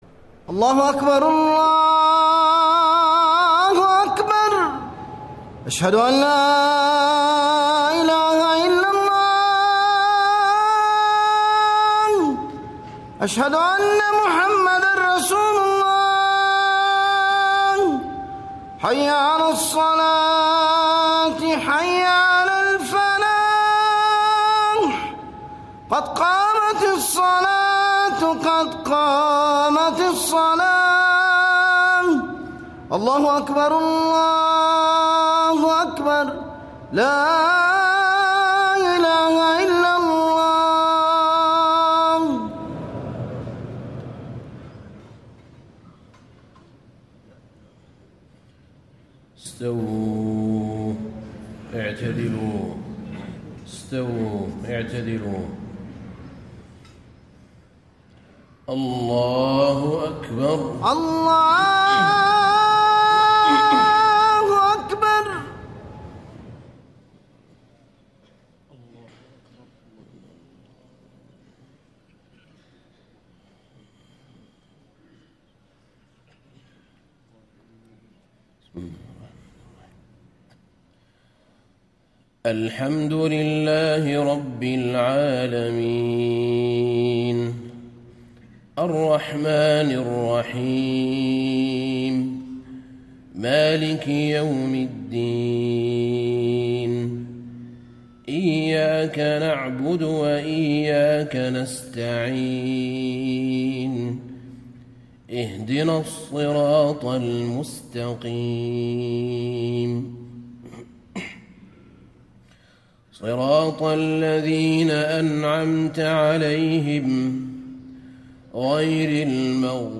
صلاة المغرب 5-2-1435 سورتي النصر و الفلق > 1435 🕌 > الفروض - تلاوات الحرمين